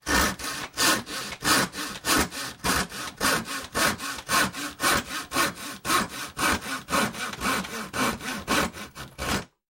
Звуки лобзика
Пилим дерево